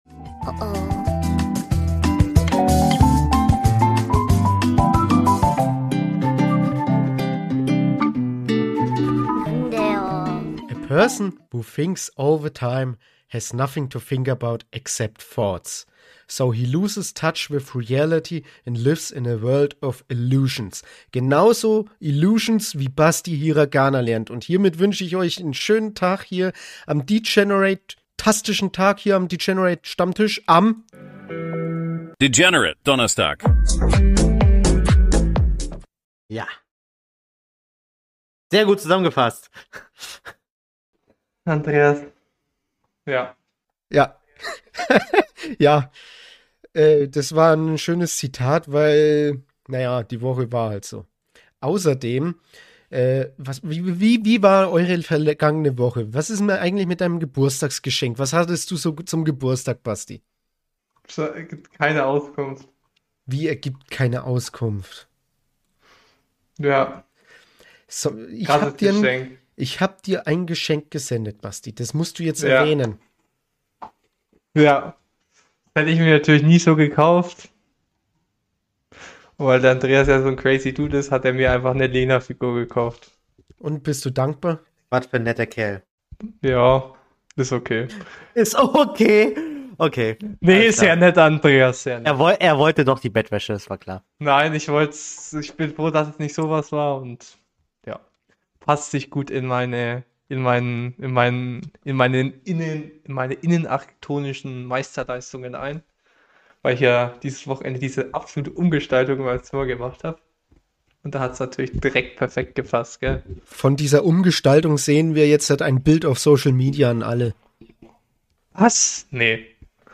Wie gewohnt gibt’s eine semi-normale Episode vom Degeneraten Stammtisch, bei der Chaos, Lachen und Anime-Talk auf die gewohnte Weise vereint werden! 🎙